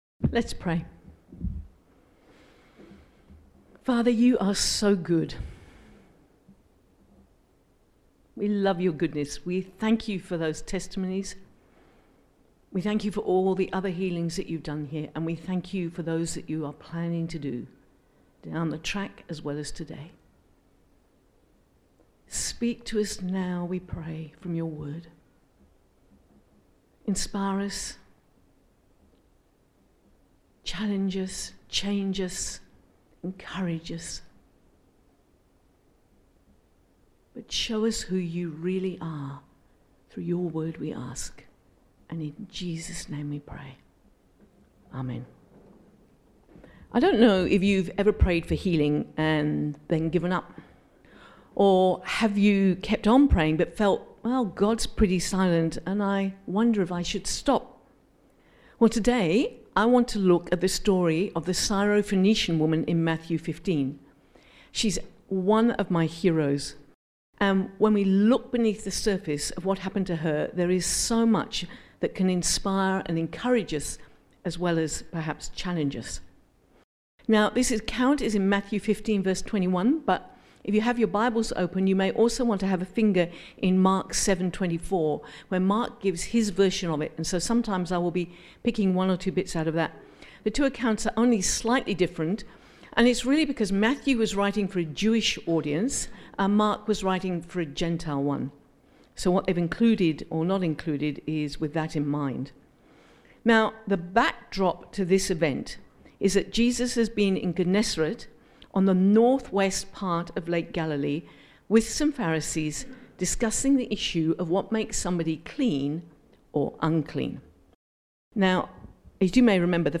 The Bible reading is Matthew 15:21-28, Mark 7:24-30.